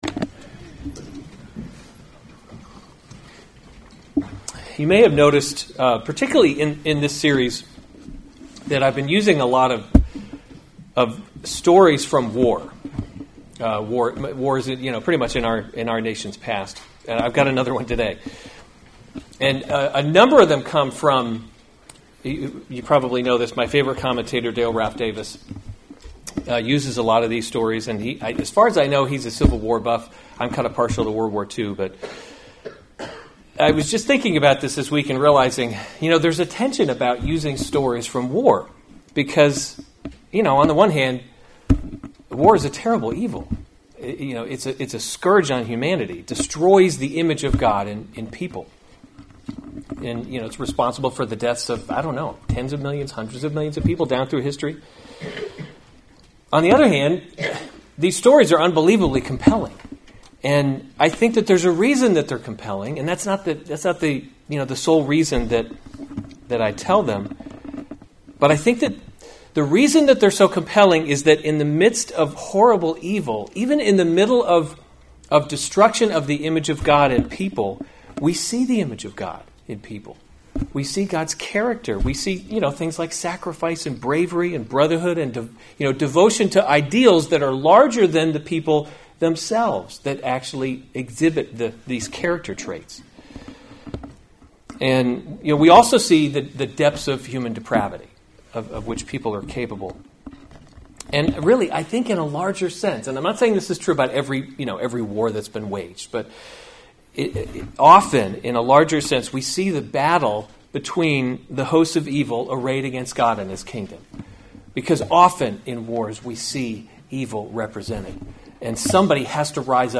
April 13, 2019 1 Kings – Leadership in a Broken World series Weekly Sunday Service Save/Download this sermon 1 Kings 16:29 – 17:1 Other sermons from 1 Kings Ahab Reigns […]